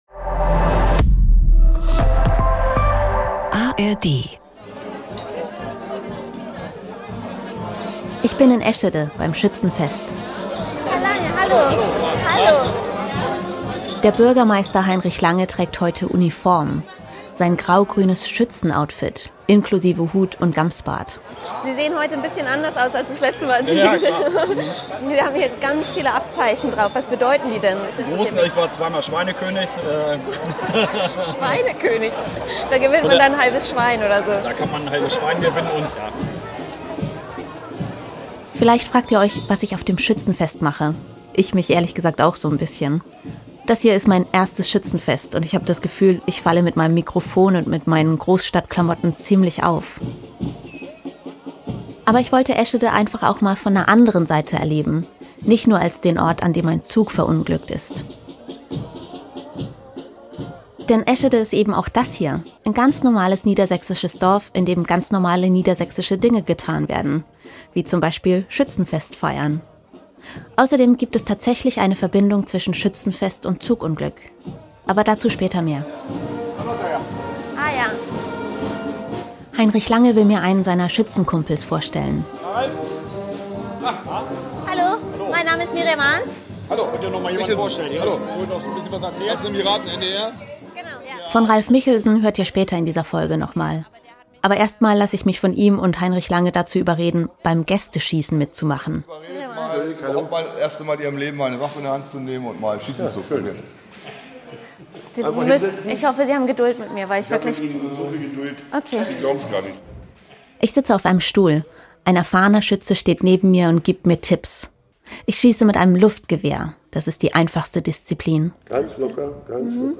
Für diesen Podcast trifft die Journalistin Überlebende und Hinterbliebene, spricht mit Anwohnern und Ersthelfern und verwebt deren Geschichten mit ihrer eigenen. Wie beeinflusst die Katastrophe noch immer das Leben der Betroffenen?